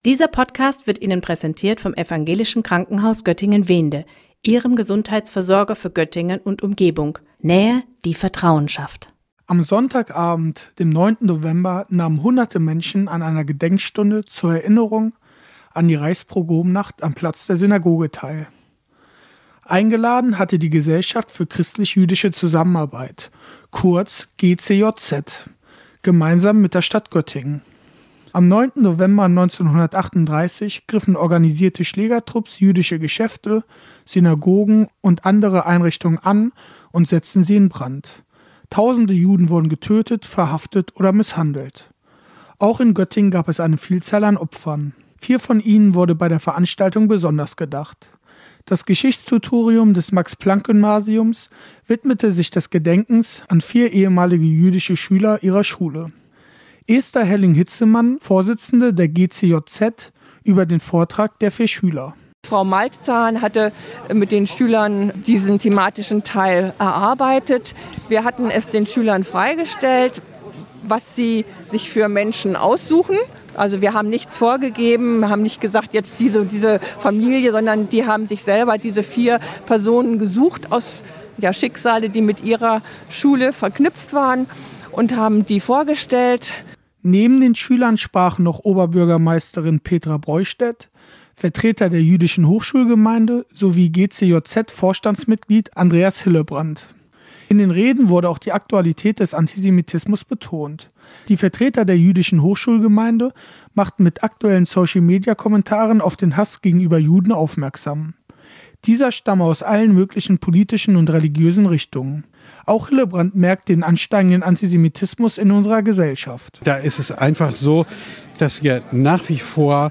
Am 9. November 1938 ermordeten die Nazis tausende Juden, plünderten ihre Geschäfte und steckten Synagogen in Brand. In Göttingen fand gestern am Platz der Synagoge eine Gedenkfeier statt.